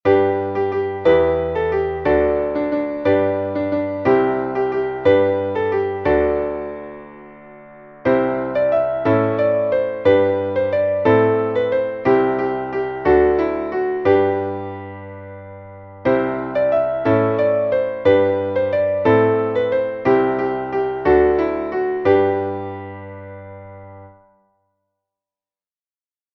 Traditionelles Volks-/ Frühlingslied